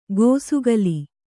♪ gōsugali